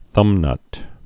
(thŭmnŭt)